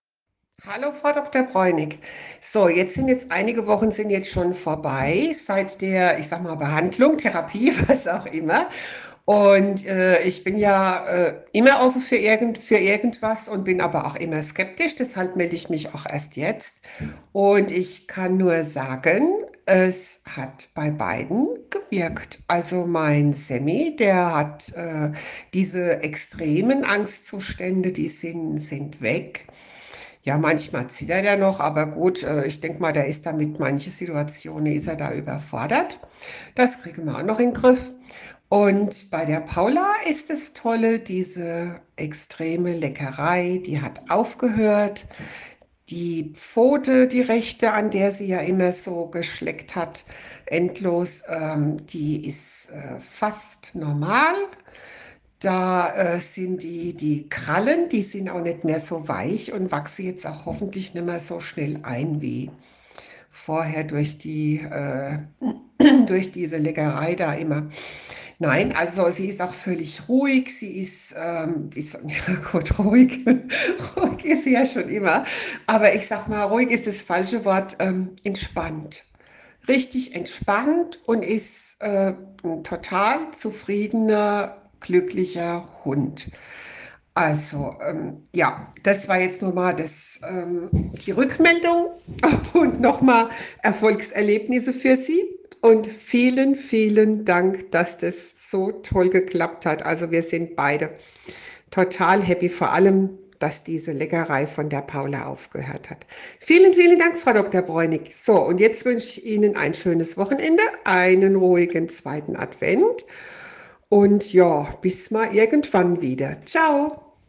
Meinung einer zufriedenen Kundin